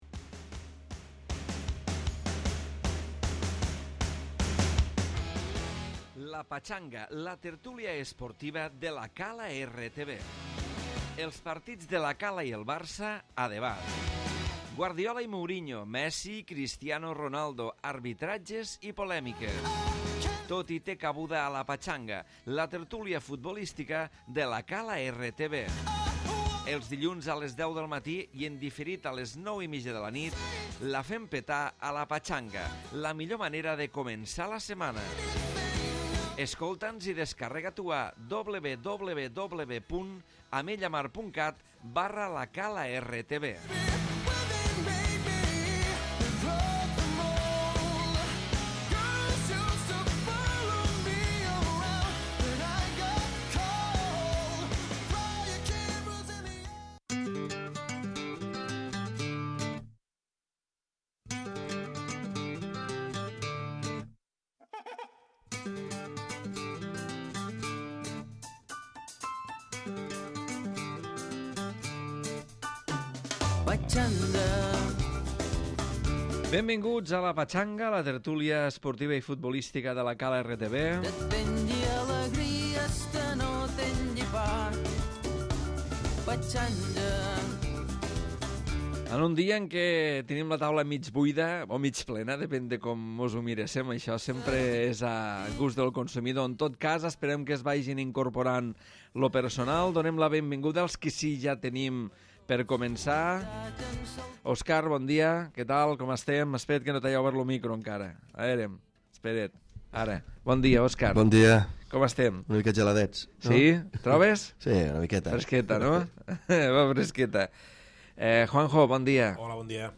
tertúlia futbolera dels dilluns